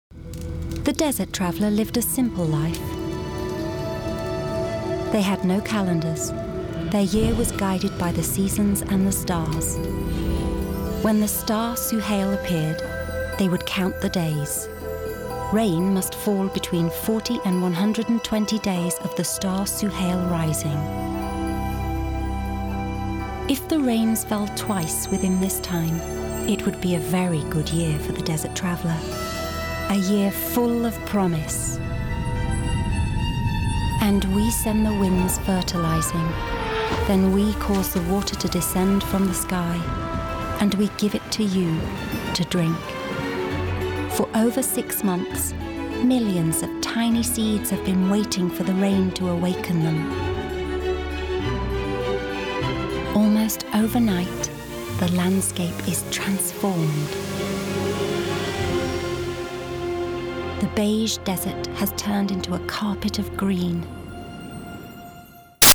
30/40's Northern/Neutral, Upbeat/Soft/Versatile
The Desert Traveller – Documentary Stone Roses – Documentary (Northern) Extreme Dog Grooming – Documentary